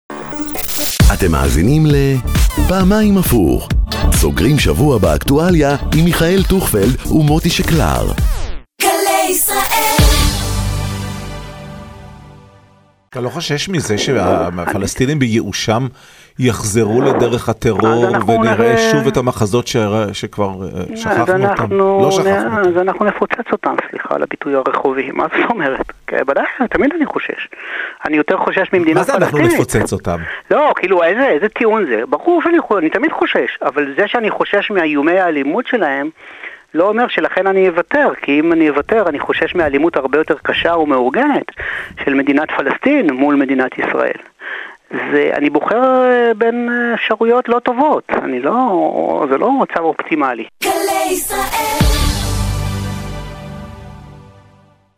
התראיין השר לאזרחים ותיקים אורי אורבך לתוכנית "פעמיים הפוך"